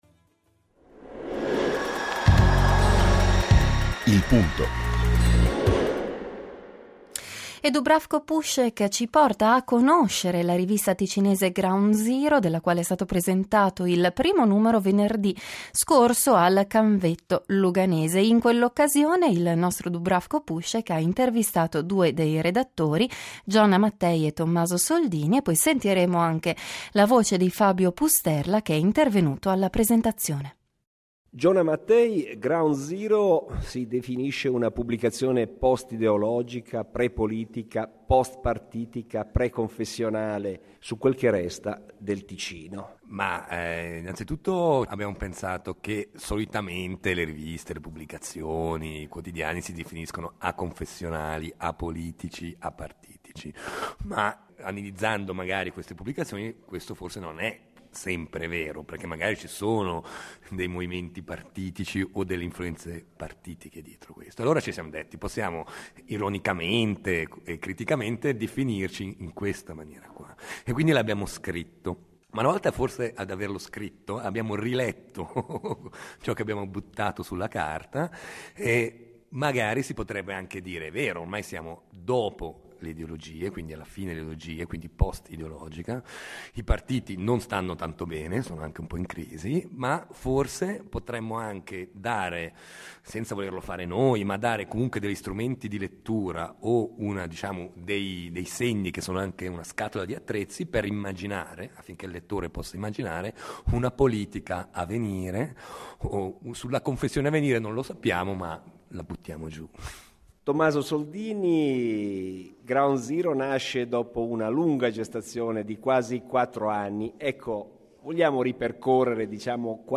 Intervista
durante la serata di presentazione del primo numero intitolato Ground Zero #01/Luoghi a Lugano.